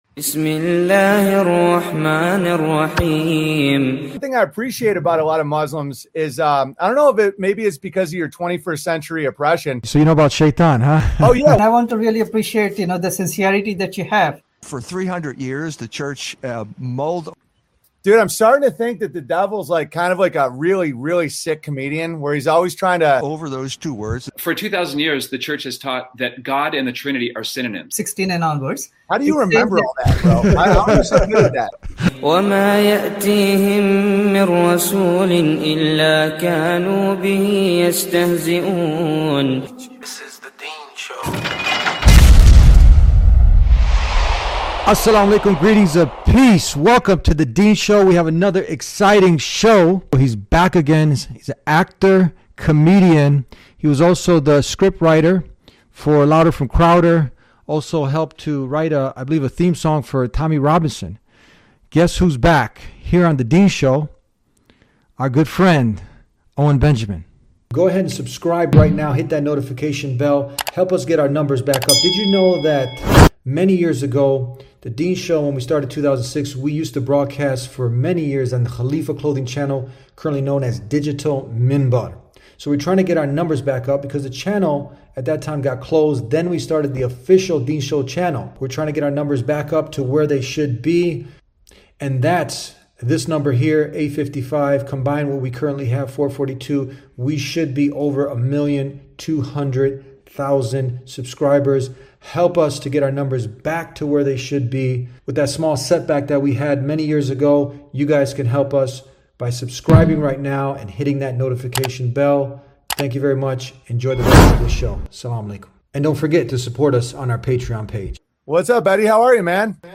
Amazing Christian Muslim Conversation about God – TheDeenshow #857 – The Deen Show